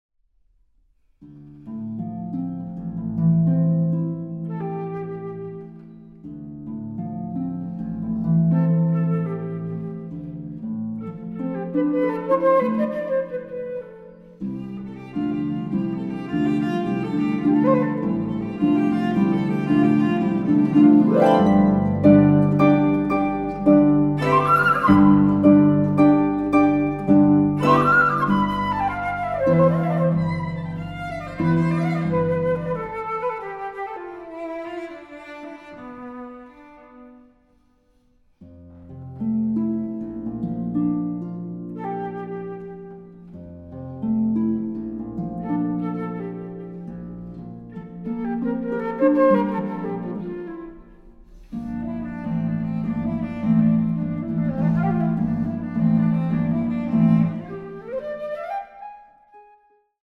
arranged for flute, cello, and harp